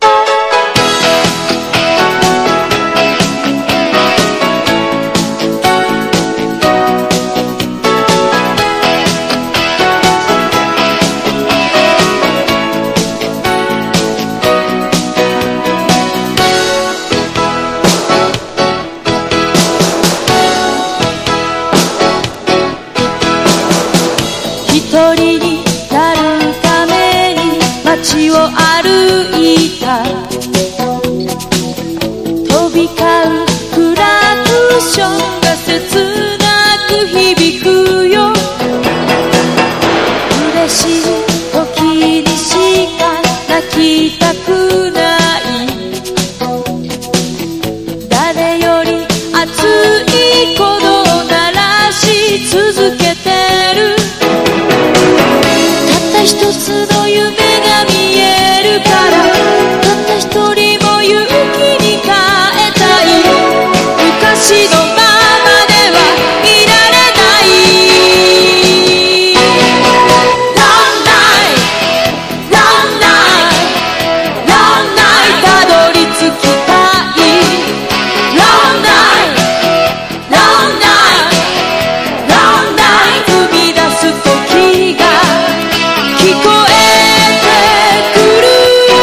# POP# CITY POP / AOR